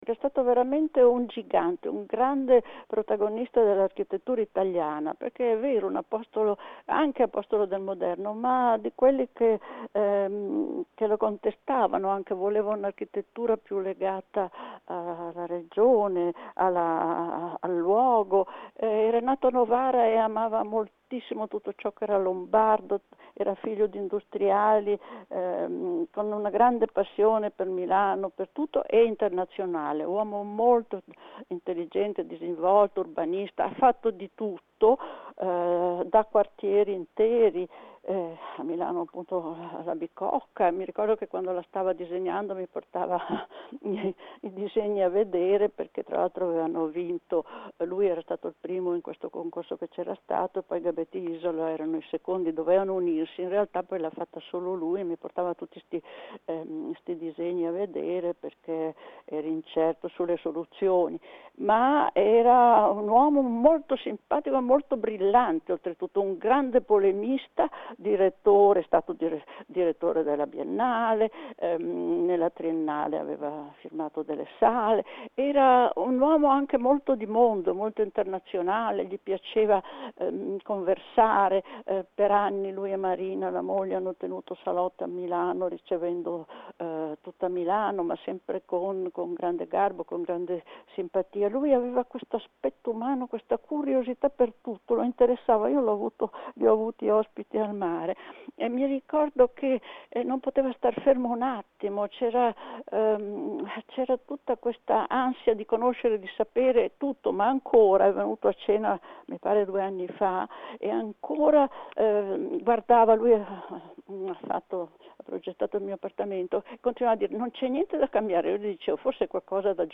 Si è spento a Milano l’architetto Vittorio Gregotti, ricoverato da qualche giorno a Milano per una polmonite da coronavirus. A Radio Popolare il ricordo della critica d’arte